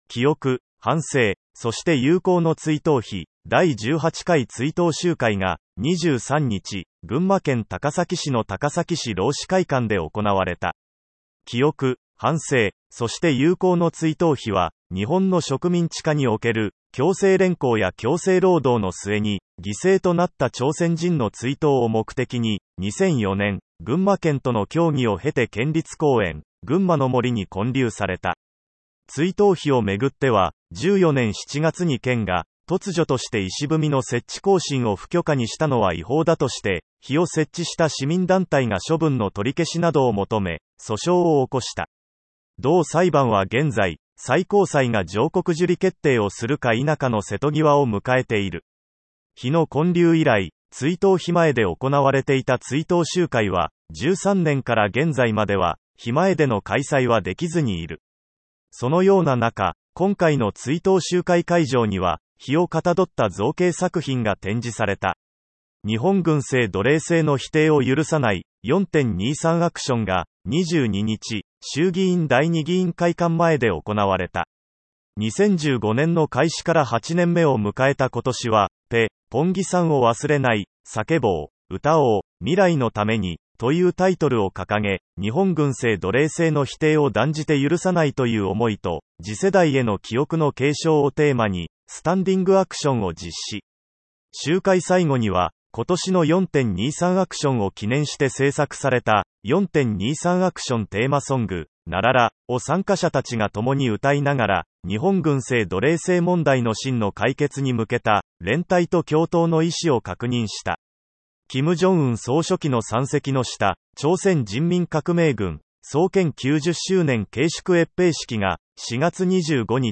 同サービスでは、1週間の主要ニュースをピックアップし、毎週日曜日にダイジェストでお届けします。
※音声読み上げソフトを導入しているため、音声ニュースの中で発音が不自然になることがあります。